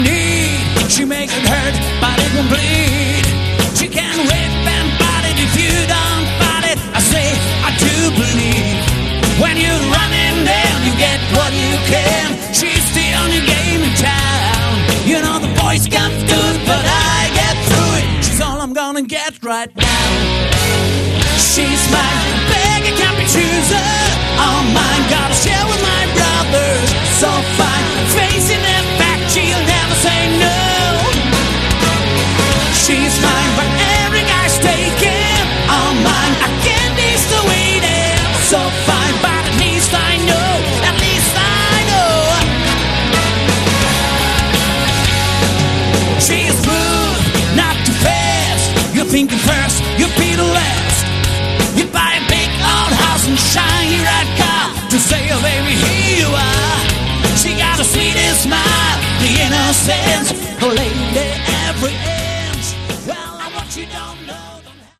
Category: Hard Rock
Straight ahead hard rock, a few cool riffs and hooks.